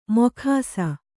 ♪ mokhāsa